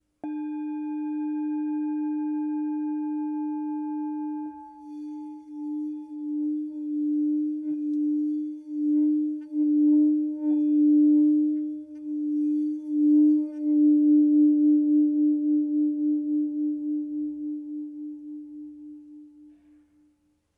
Tibetská mísa Chu střední
Tepaná tibetská mísa Chu o hmotnosti 817 g. Mísa je včetně paličky s kůží!
Součástí tibetské mísy Chu je i palička na hraní.
tibetska_misa_s34.mp3